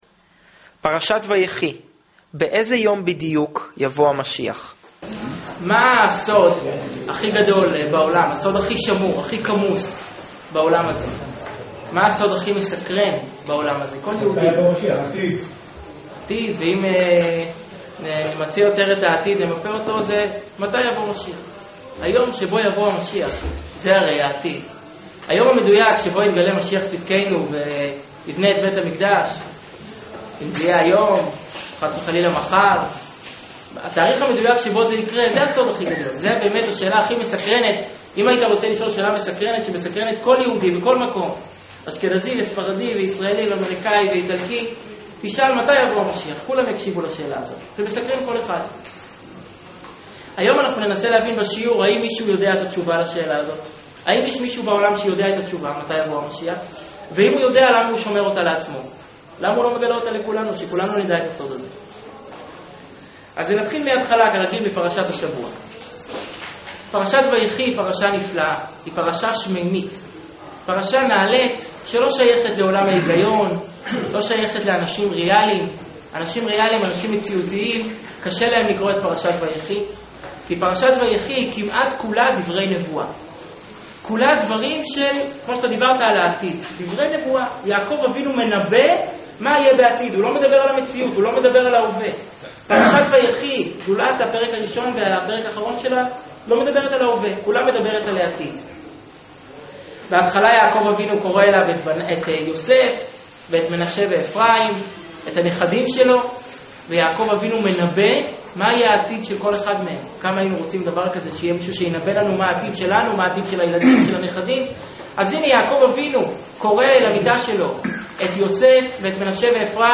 שיעור מרתק לפרשת ויחי
שנמסר בביהכנ"ס חב"ד בראשל"צ